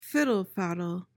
PRONUNCIATION:
(FID-uhl-fad-uhl)